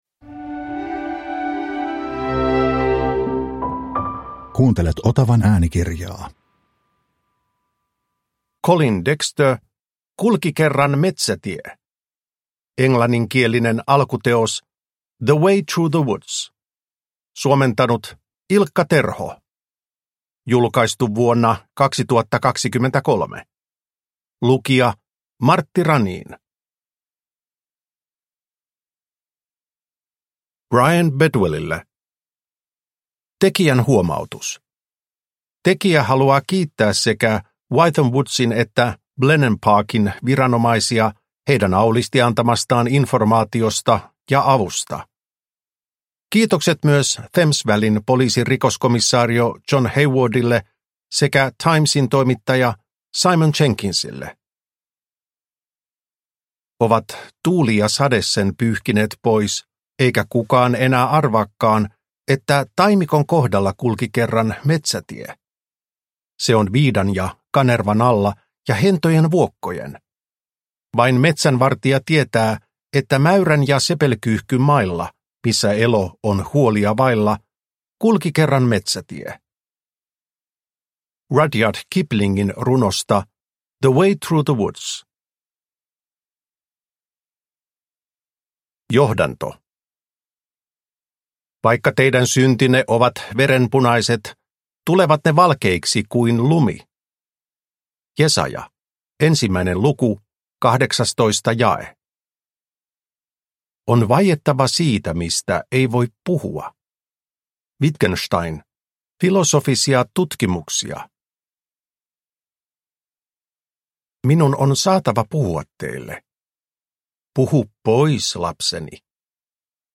Kulki kerran metsätie – Ljudbok – Laddas ner